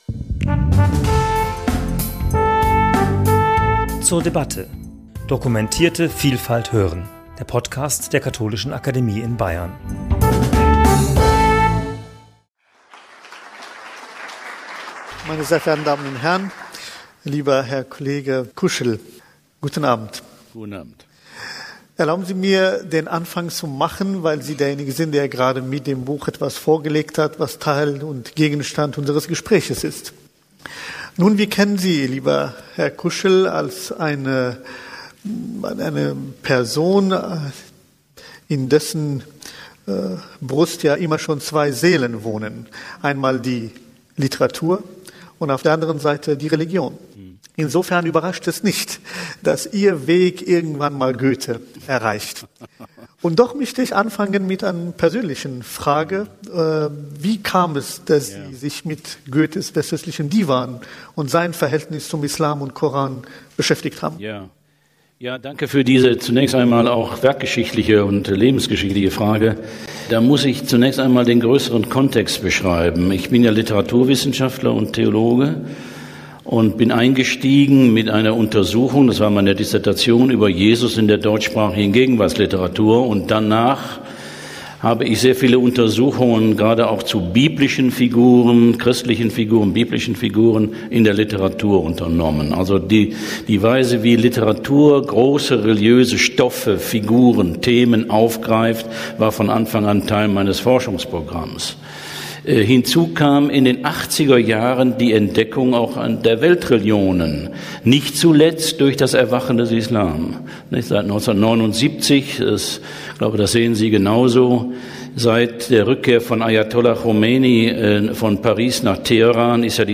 Gespräch zum Thema 'Goethe und der Koran' ~ zur debatte Podcast